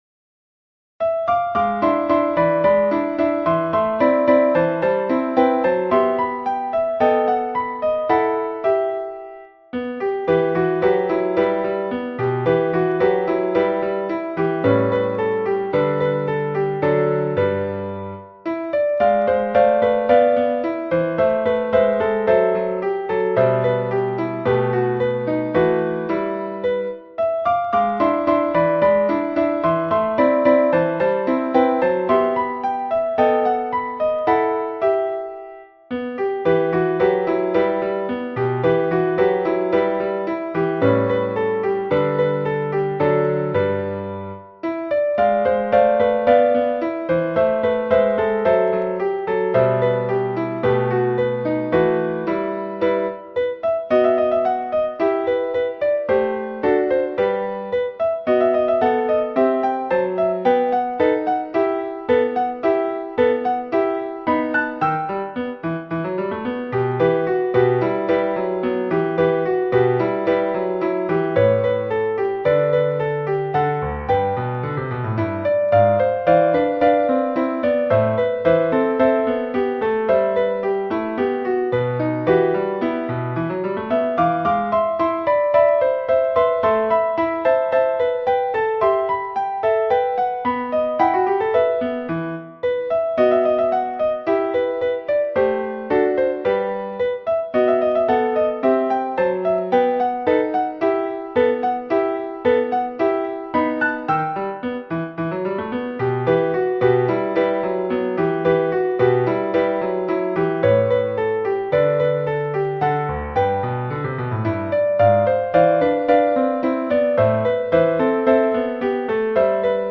Ноты для фортепиано в формате: